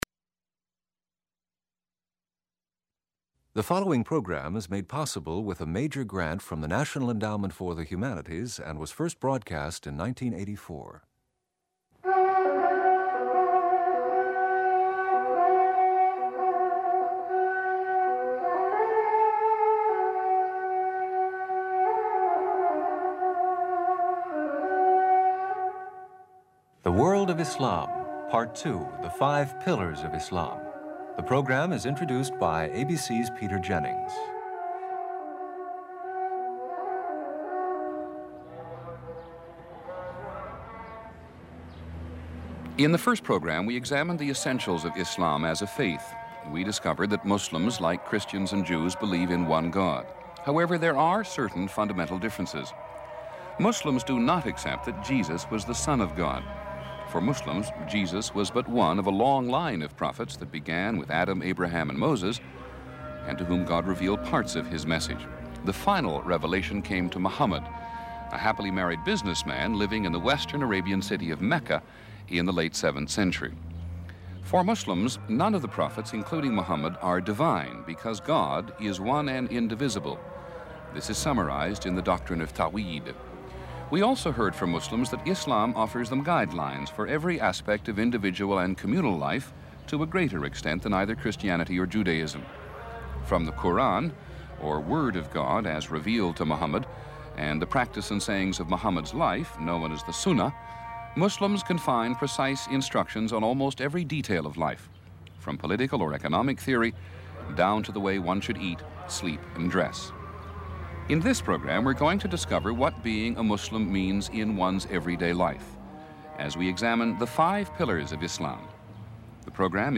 People interviewed in this program